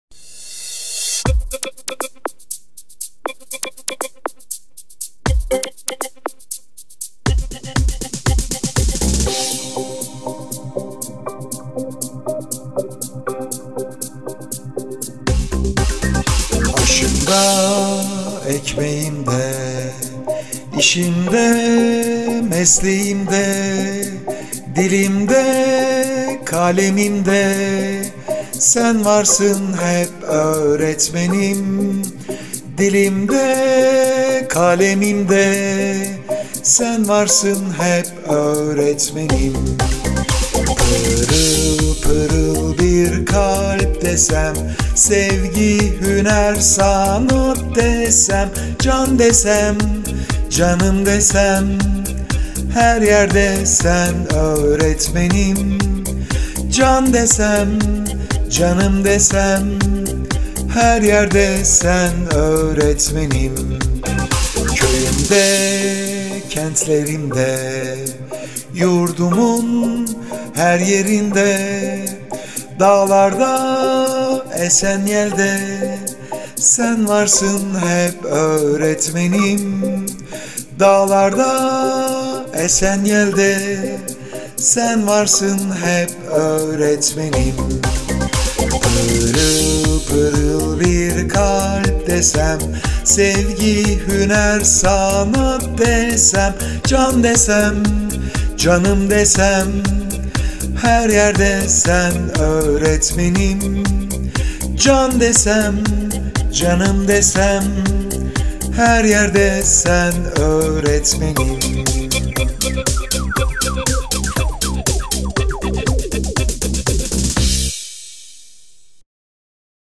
SÖZLÜ ÇALIŞMA KAYDI